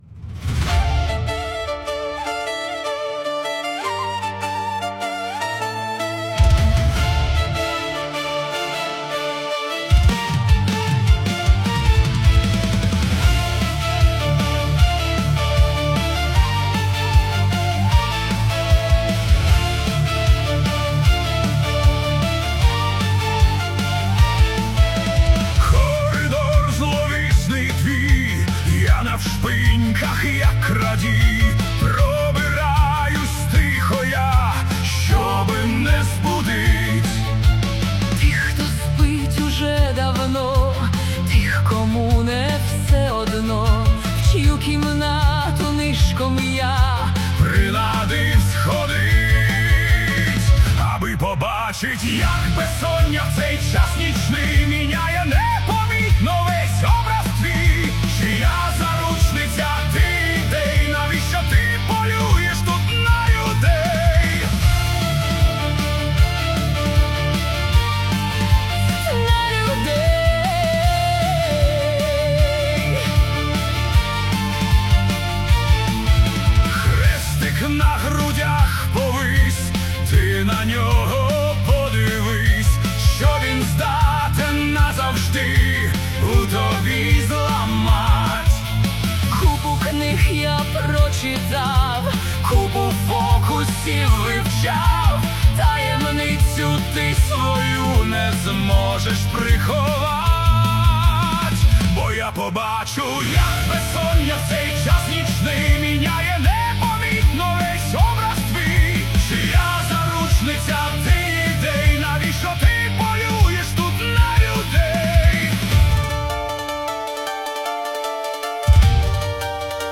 Українська рок адаптація